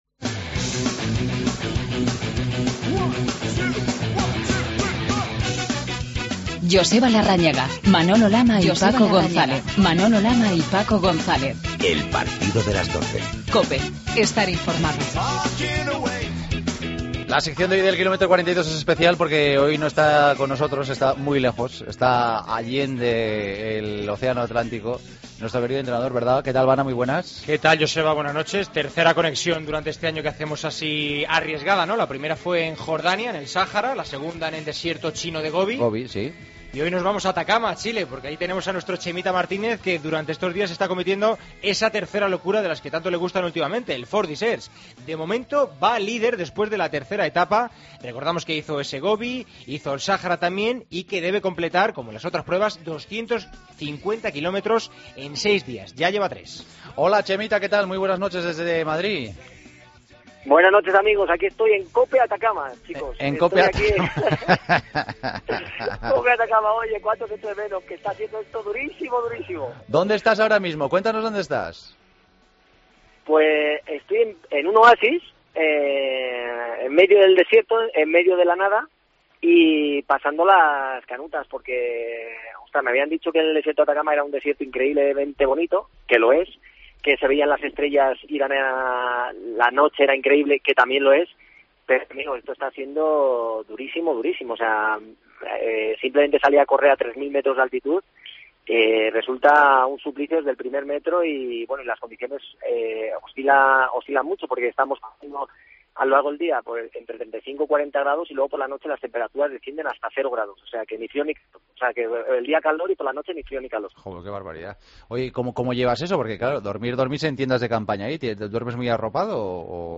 Chema Martínez hace este 'Kilómetro 42' desde el Atacama, donde está corriendo a más de 3000 metros de altura bajo un calor asfixiante.